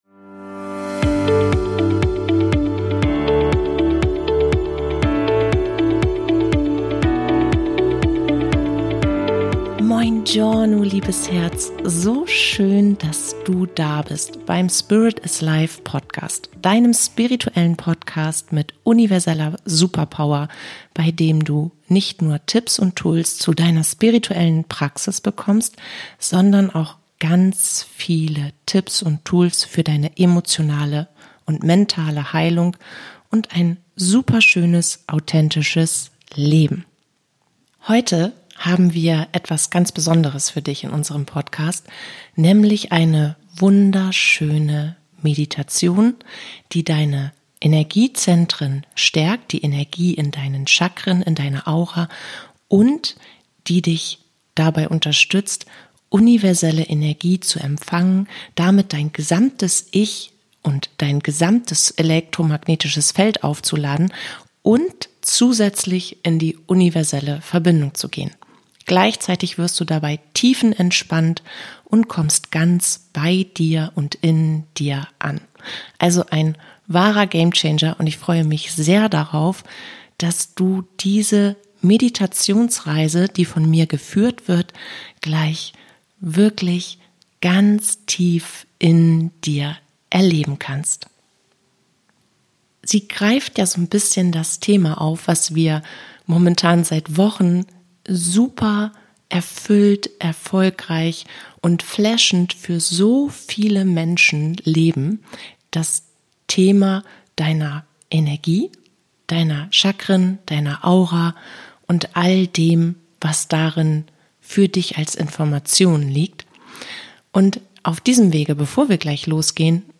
Energetisch programmierte Meditationsreise ~ Spirit is Life Podcast